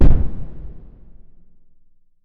Casual Game Sounds